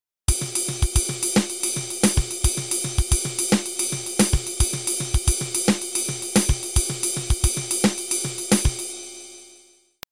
This groove is amazing.
I’ve included the MIDI version at two tempos to show how the feel of it changes with the tempo.
mozambique.mp3